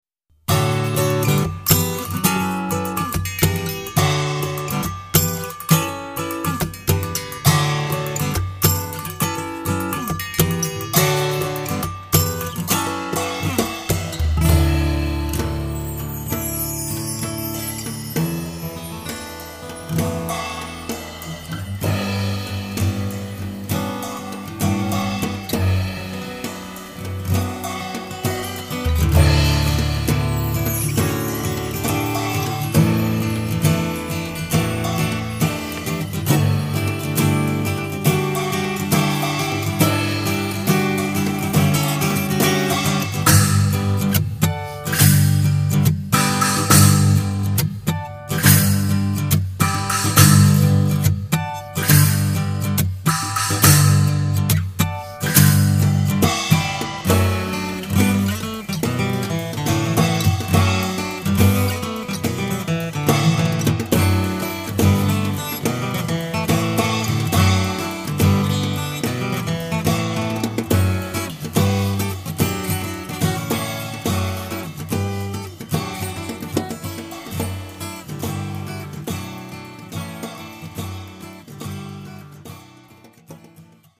パーカッションとギターによる最小単位のオーケストラが奏でるのは
guitar